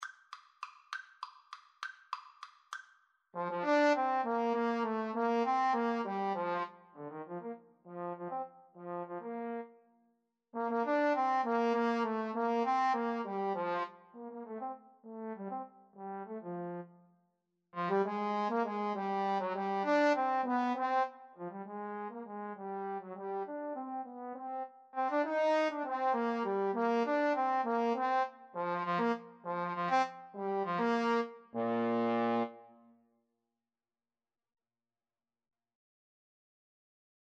3/8 (View more 3/8 Music)
Classical (View more Classical Trombone Duet Music)